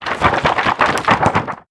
CartoonGamesSoundEffects
Shake_v5_wav.wav